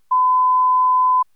ettusenfemton.wav